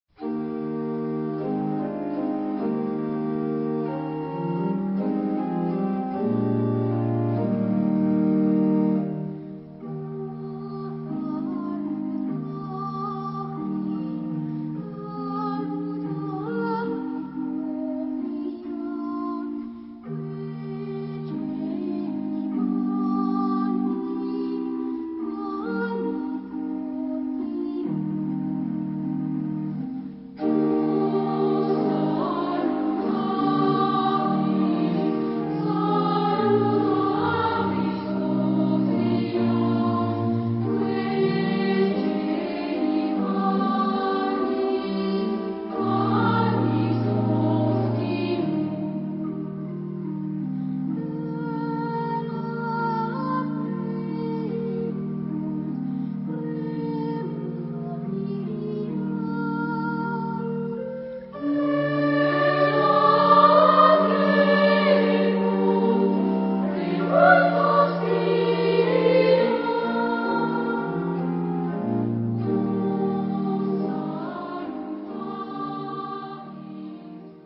Genre-Style-Form: Mass ; Sacred ; Romantic
Type of Choir: SA  (2 women voices )
Tonality: G minor